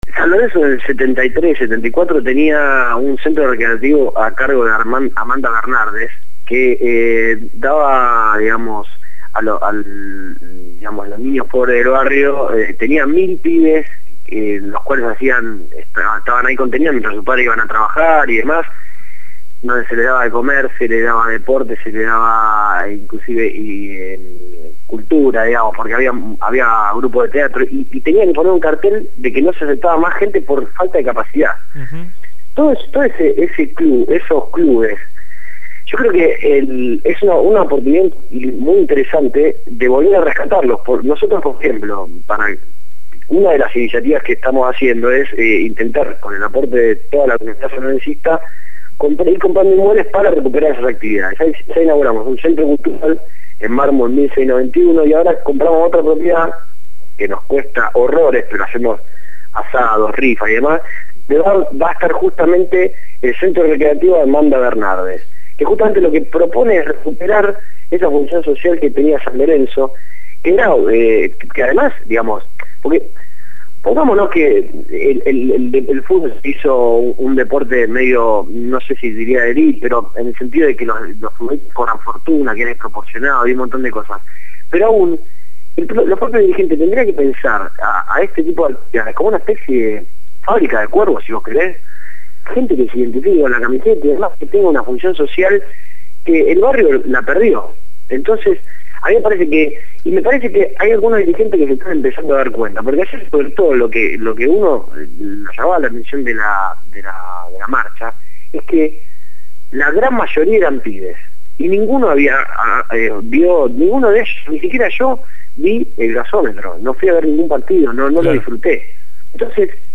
dialogó con el programa «Desde el barrio» (lunes a viernes de 9 a 12 horas) por Radio Gráfica FM 89.3